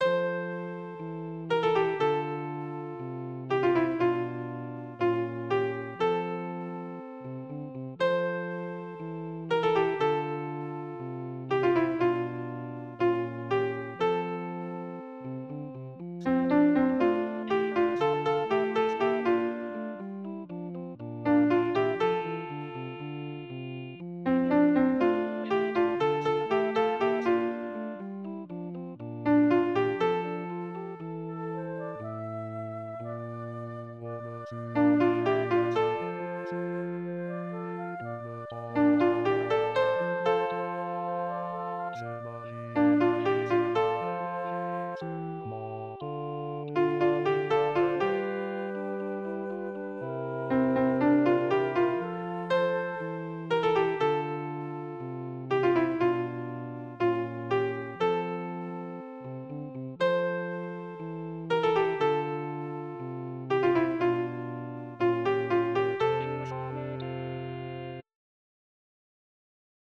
Soprano (.mp3) Alto (.mp3)
Voix au piano et les autres en sourdine chantées